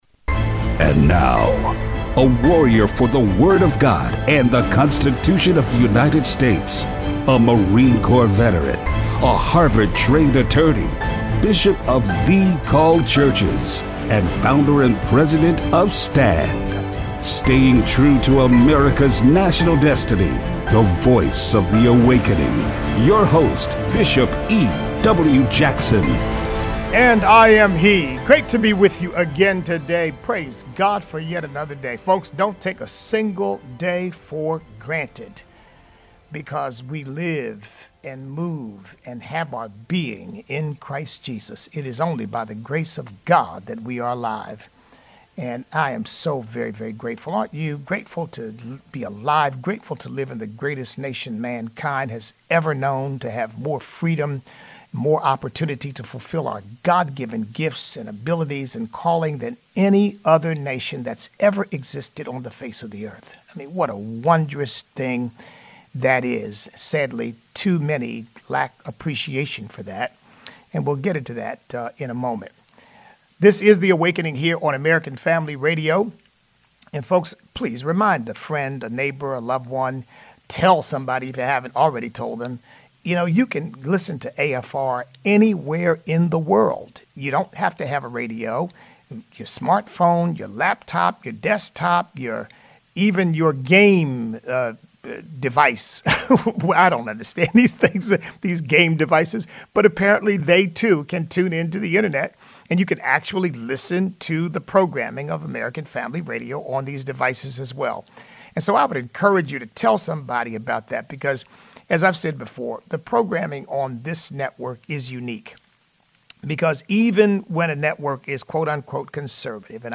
Political correctness and Communism. Listener call-in.